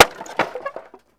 wood_plank_break6.wav